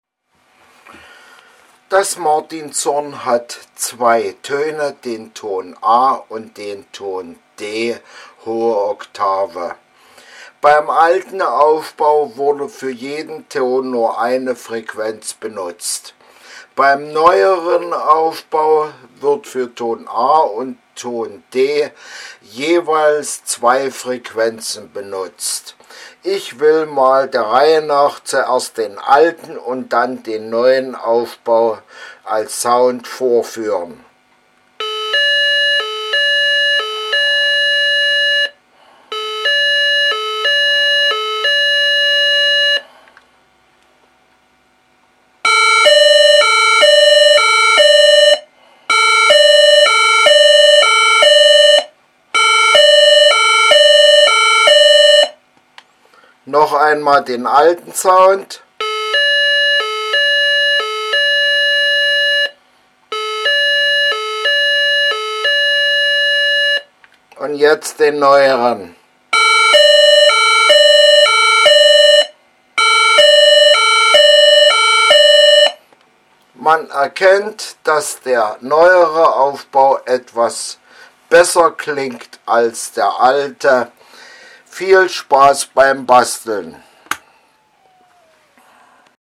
6. Besseres Soundmodul "Martinshorn oder Feuerwehr-Signal" als Bastel-Lösung
Ein Feuerwehrsignal arbeitet mit 2 Frequenzen bei beiden Tönen.
Ton A 450Hz und 435Hz
Ton D 580Hz und 600Hz
Es hört es sich auf jeden Fall besser an als der alte Aufbau.
Soundvergleich-Feuerwehrsignal.mp3